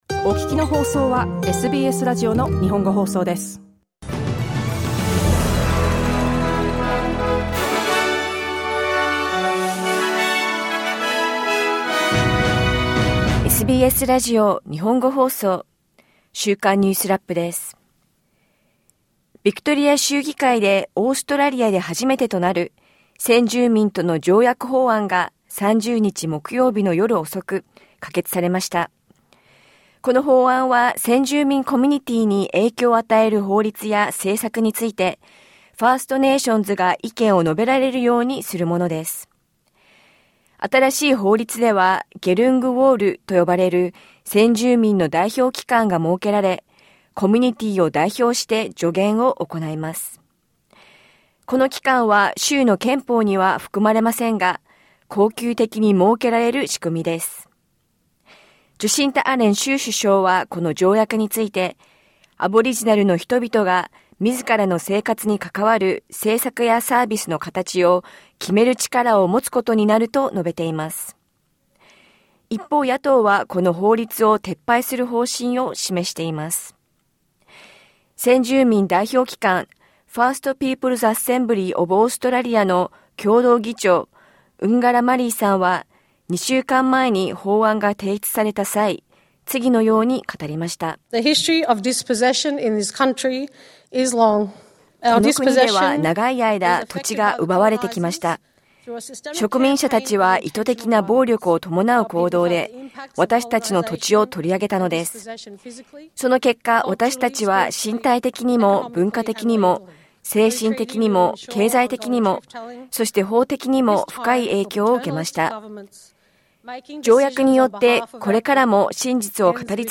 ビクトリア州議会で、先住民との歴史的な条約が可決されました。チャールズ国王の弟、アンドリュー氏が、王子の称号を剥奪され、王室の公邸を退去するよう求められました。オーストラリアで数十年ぶりとなる、環境法の改正案が、国会に提出されました。1週間を振り返るニュースラップです。